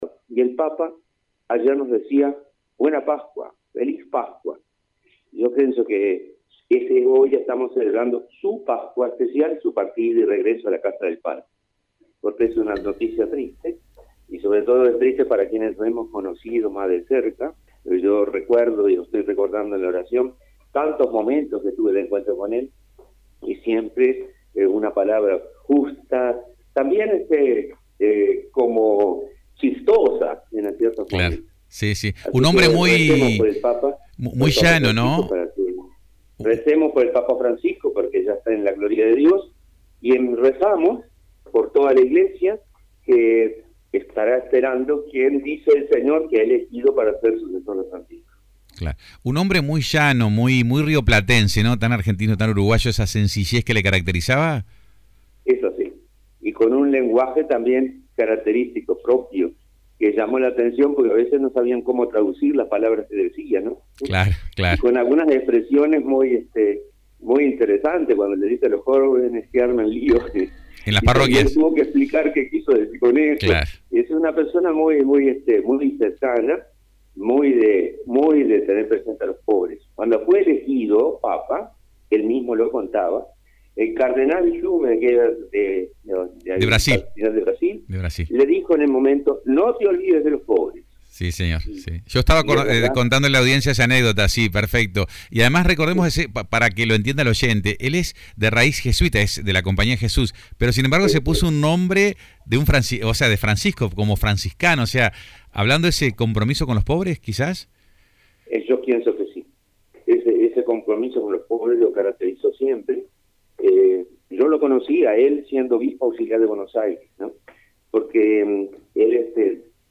También dialogamos con Mons. Carlos María Collazzi quien recordó la figura del pontífice y algunos de los encuentros que mantuvieron en el tiempo.